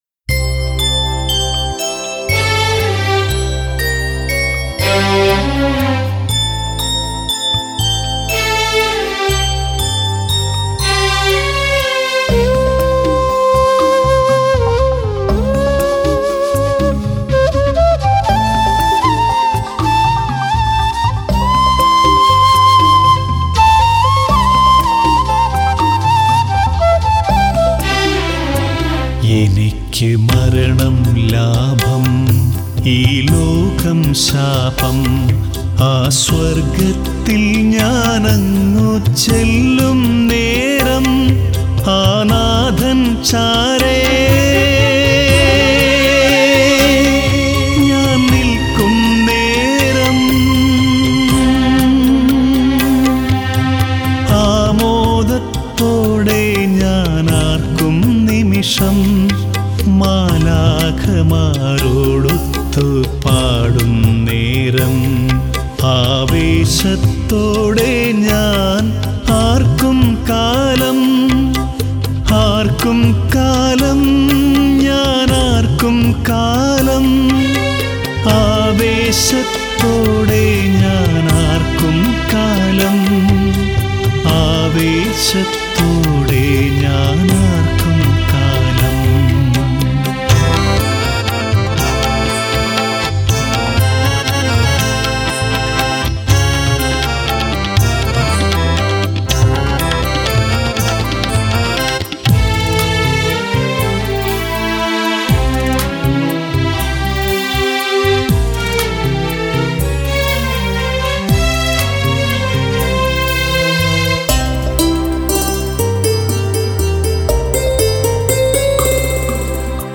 Keyboard Sequence
Rhythm Sequence
Flute
Tabala & Percussion